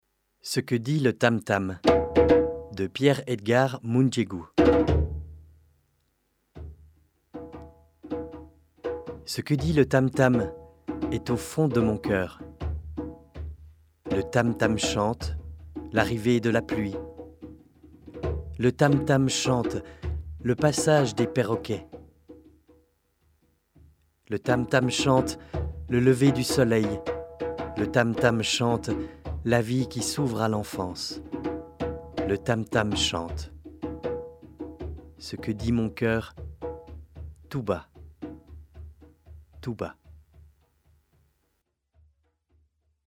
Fichier audio du poème utilisé dans le parcours Le poème mis en voix FRA 3-4